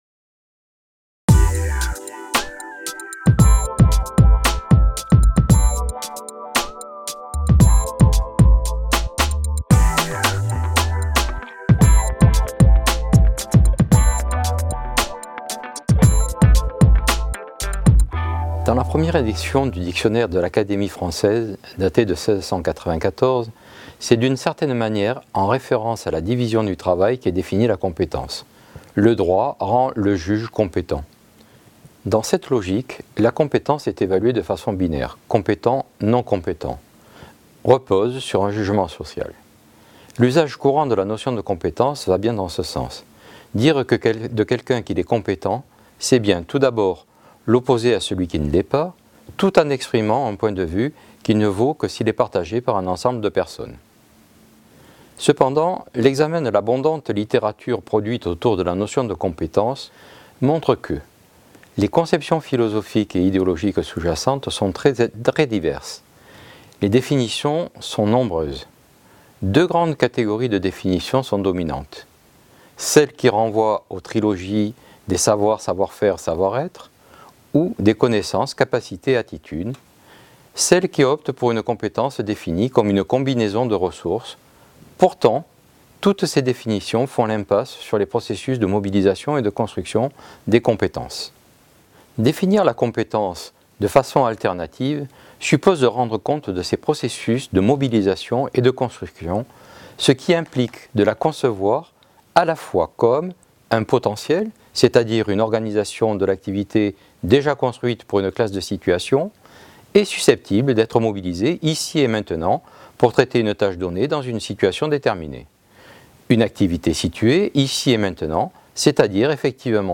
Vidéo pédagogique